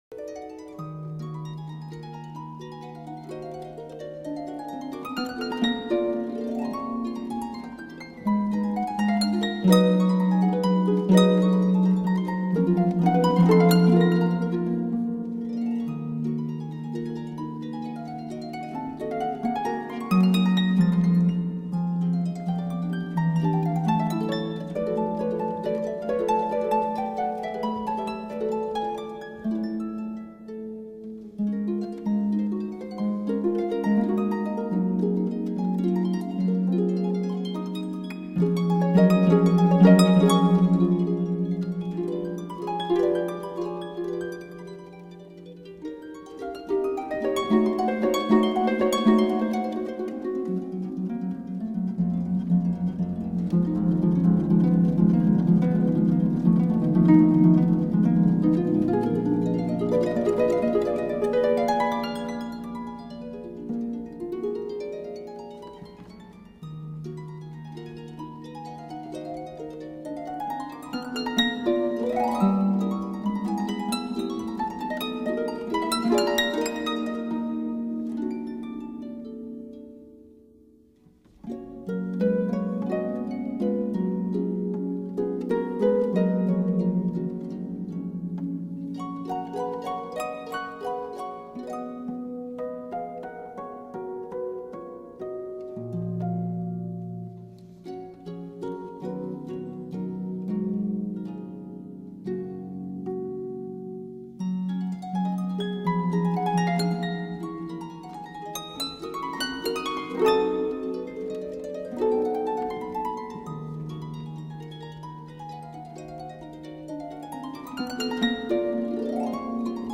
本专辑作品录音技术紧密饱满，成功捕